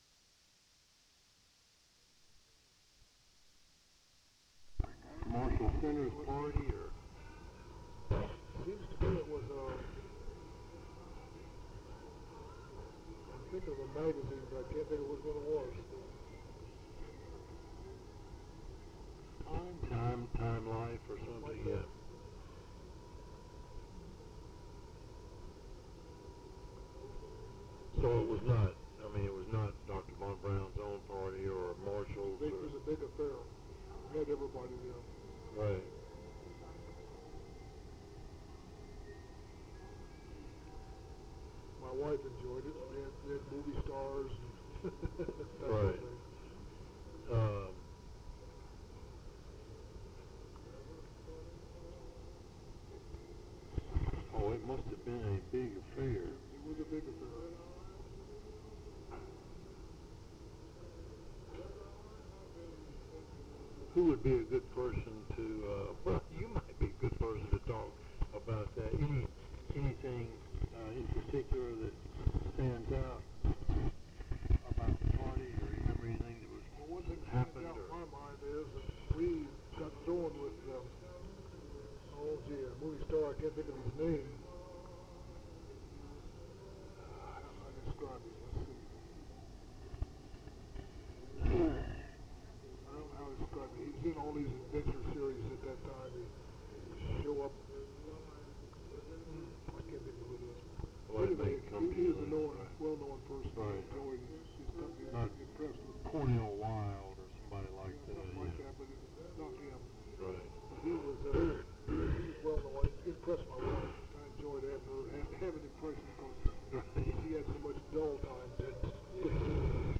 They discuss stories relating to Wernher von Braun over lunch. Mentioned are von Braun's meeting style, speeches, salesmanship, and how he handled publicity.
Interviews
Oral History